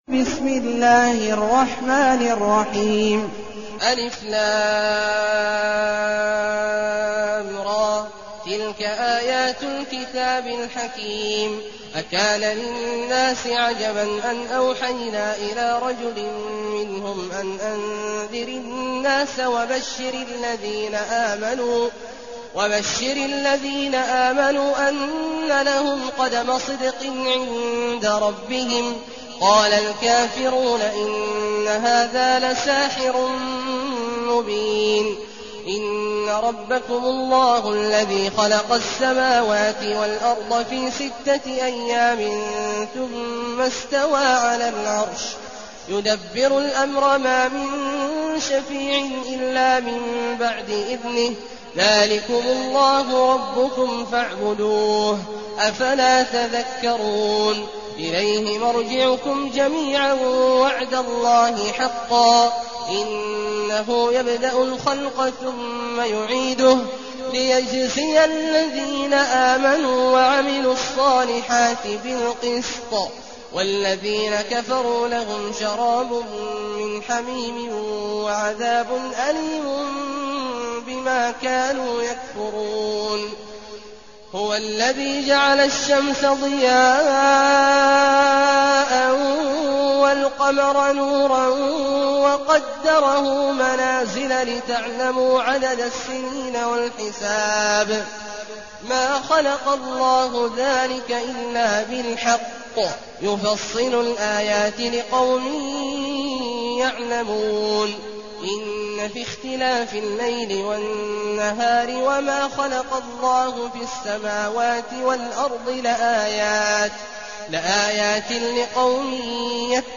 المكان: المسجد النبوي الشيخ: فضيلة الشيخ عبدالله الجهني فضيلة الشيخ عبدالله الجهني يونس